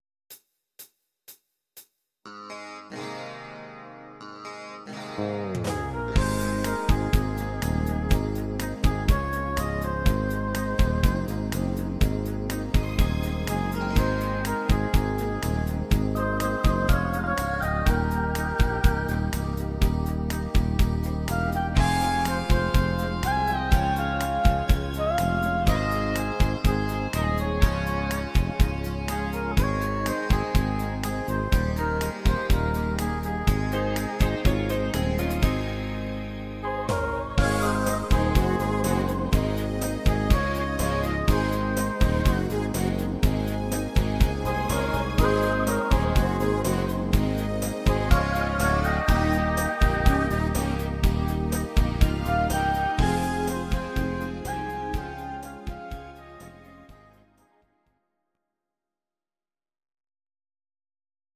Audio Recordings based on Midi-files
German, 2000s